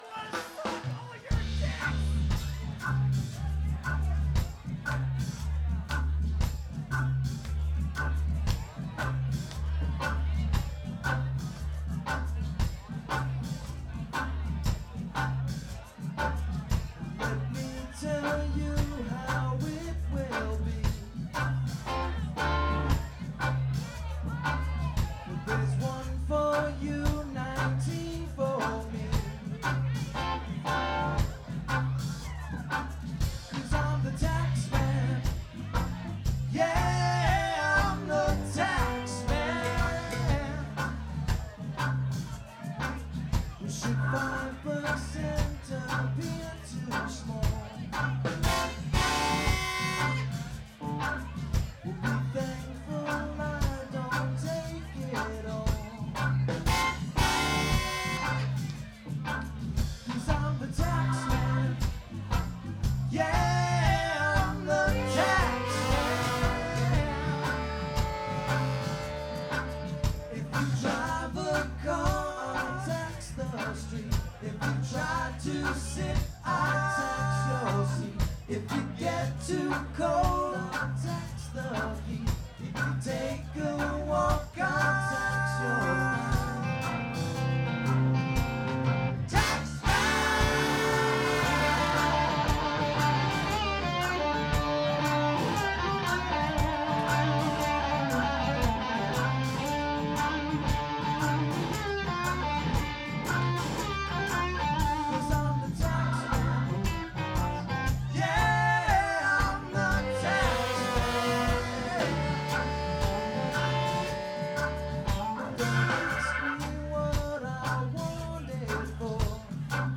Live at 8×10